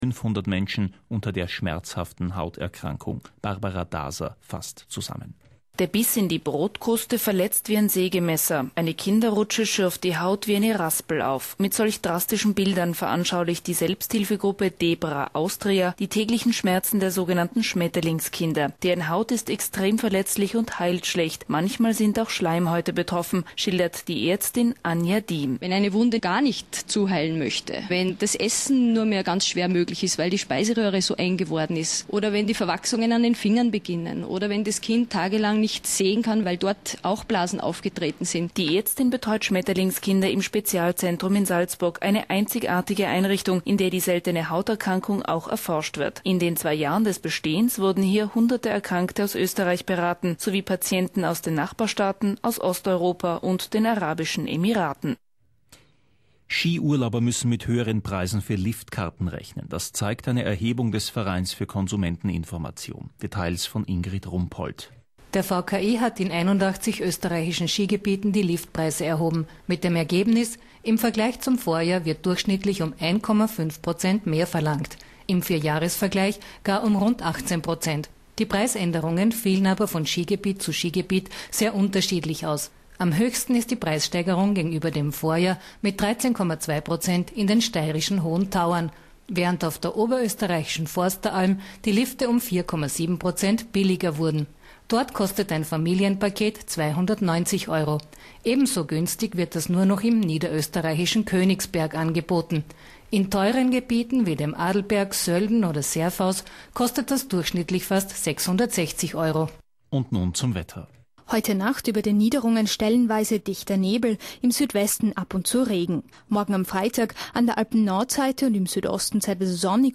Interview über Yerkish.